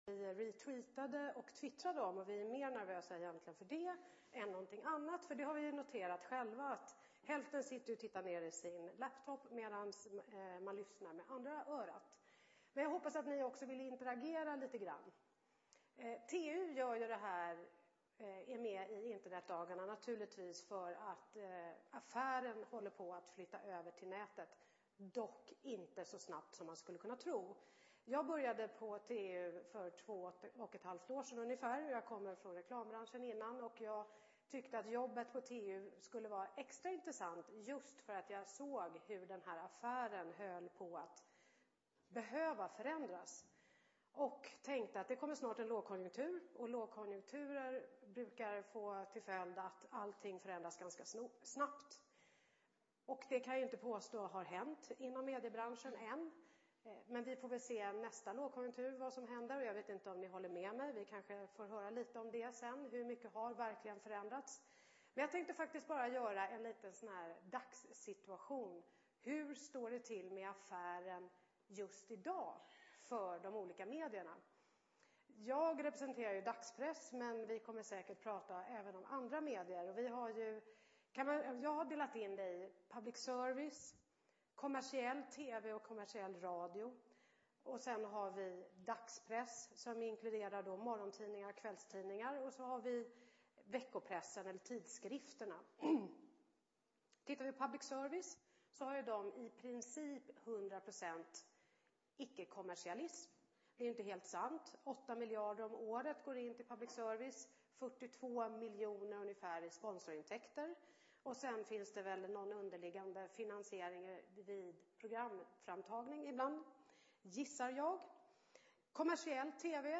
Plats: Kongresshall B